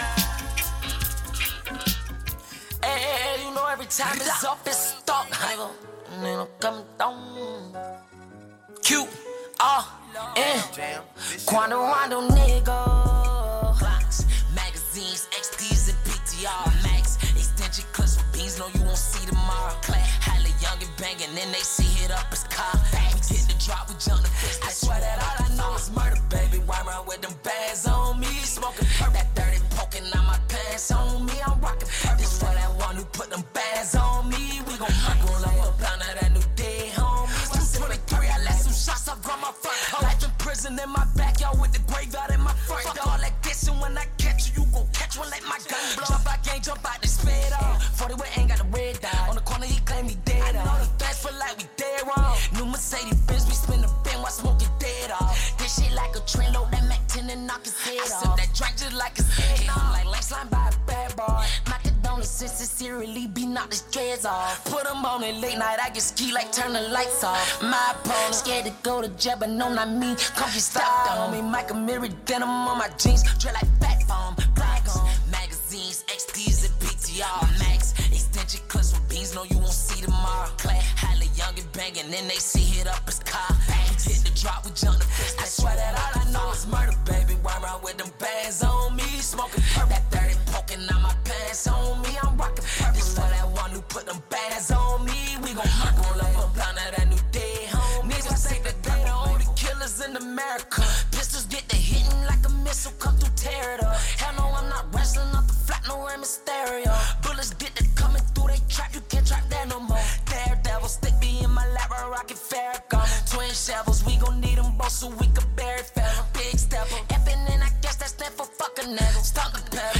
Free Underground Web-Radio,